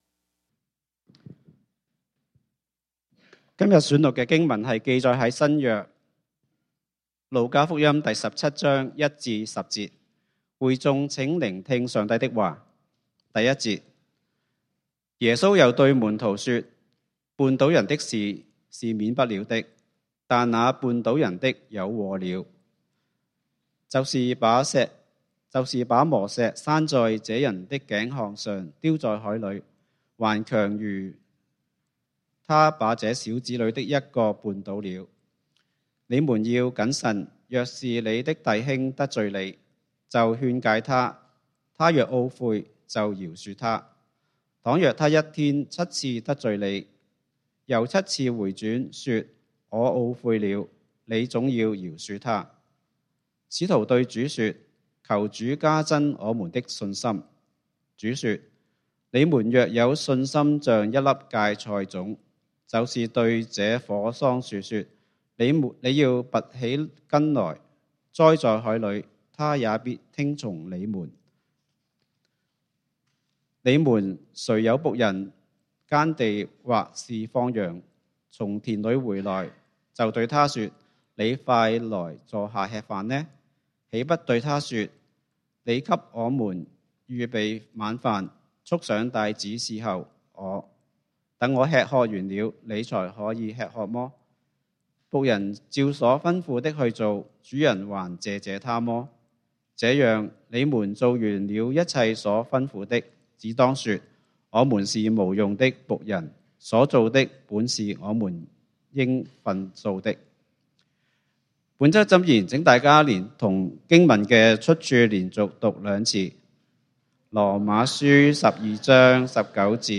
10/5/2025 講道經文：路加福音 Luke 17:1-10 本週箴言：羅馬書 Romans 12:19 親愛的弟兄，不要自己伸冤，寧可讓步，聽憑主怒； 因為經上記着： 「主說：『伸冤在我，我必報應。』」」